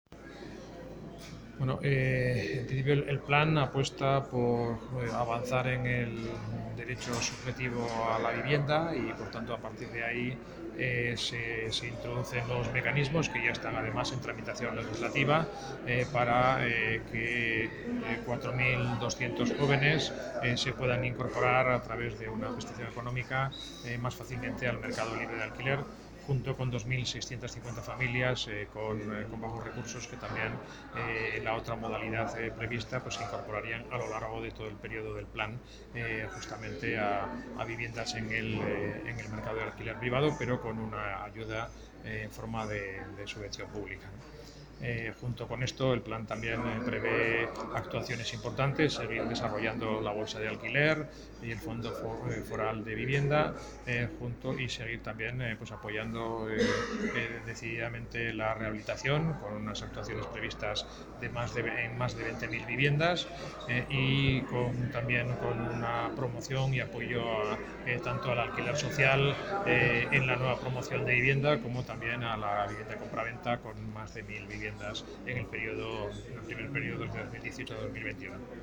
Declaraciones del vicepresidente de Derechos Sociales, Miguel Laparra